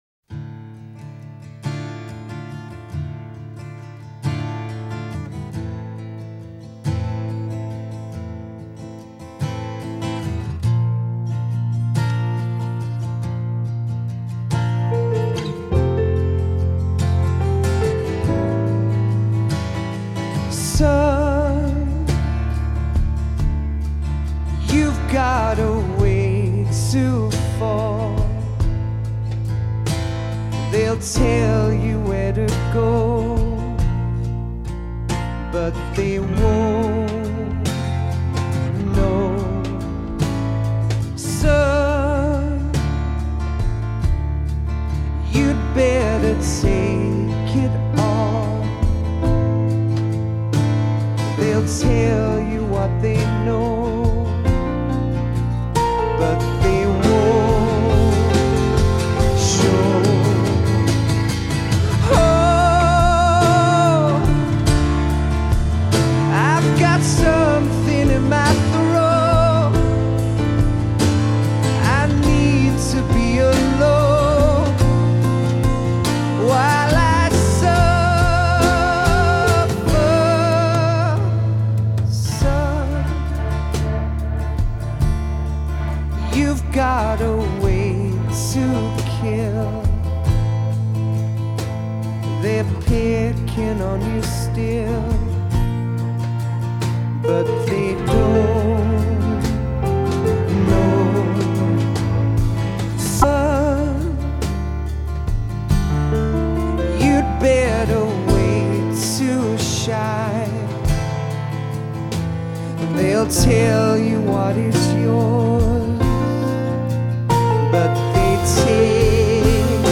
许久许久没有听到这么真挚的歌声了。